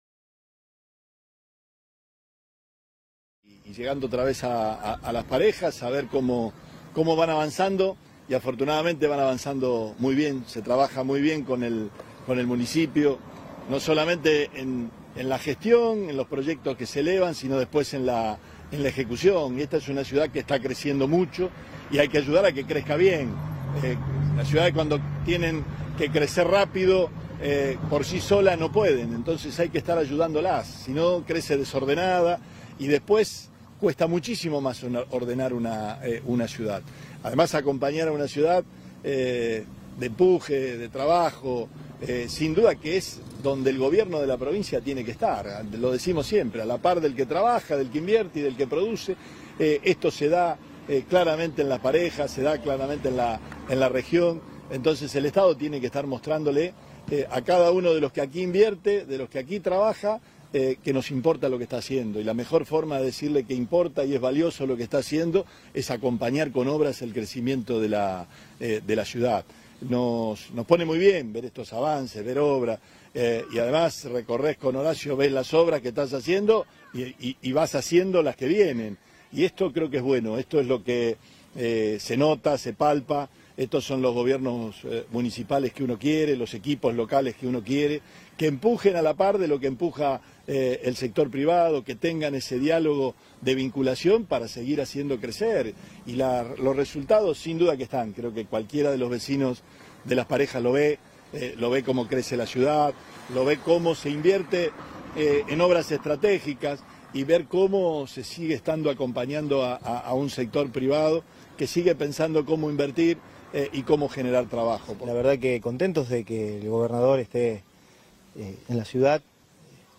En Las Parejas